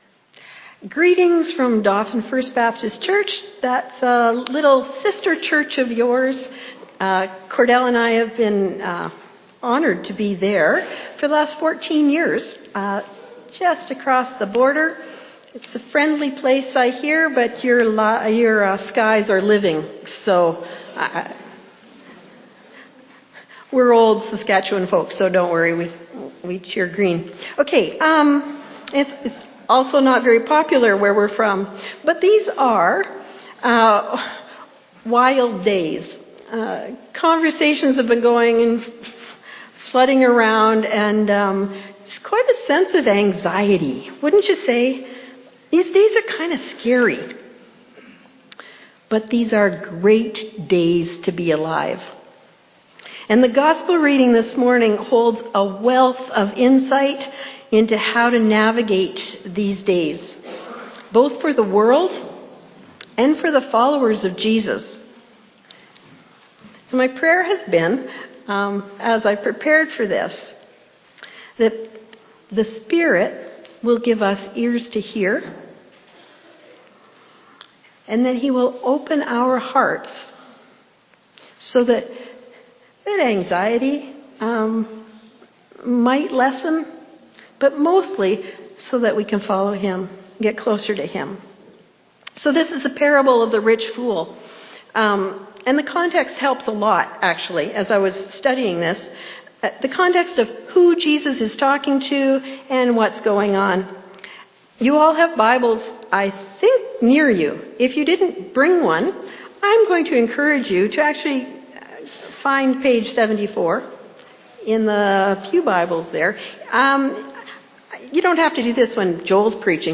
fbcsermon_2025_Mar2.mp3